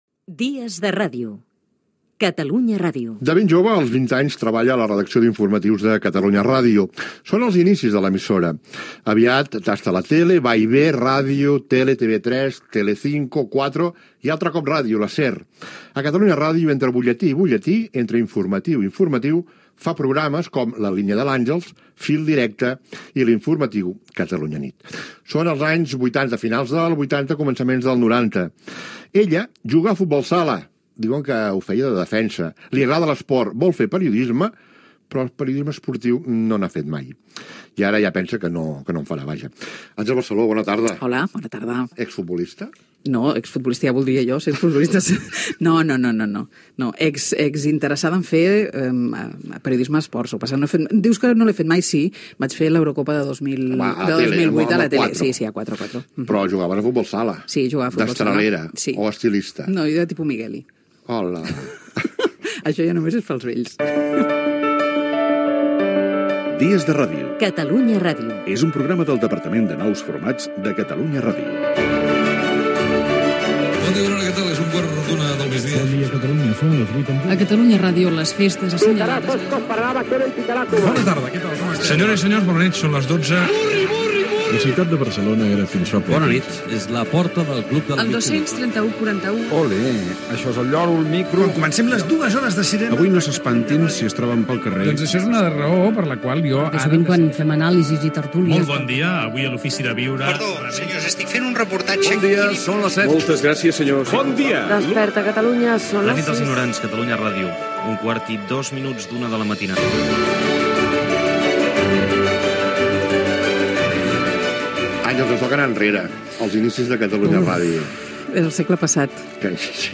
Presentació inicial, careta del programa, entrevista a la periodista Àngels Barceló sobre els seus inicis a Catalunya Ràdio